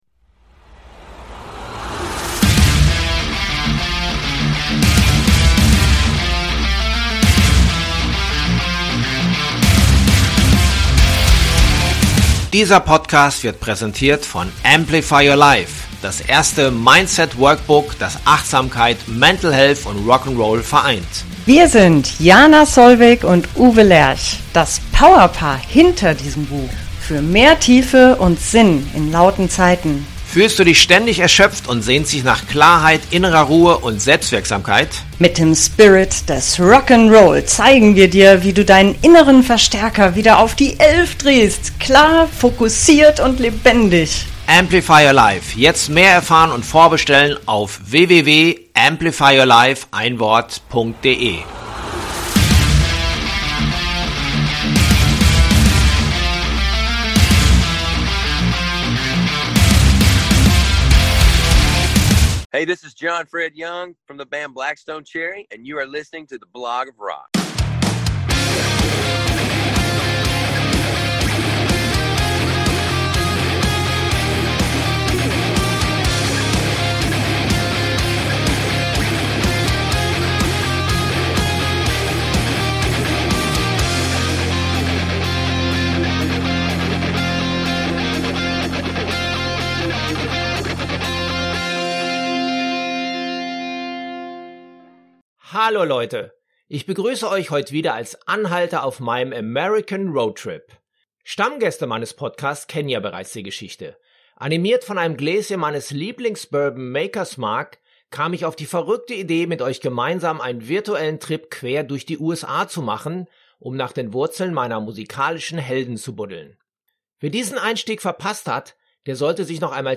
In der heutigen Episode treffen wir John Fred Young von BLACK STONE CHERRY . Wir sprechen über das letzte Album „The Human Condition“, aber auch um seine neuen Programmierkünste während der Produktion, um American Football und seinen großen Wunsch: Einmal Bundesliga-Fußball live in einem deutschen Stadion!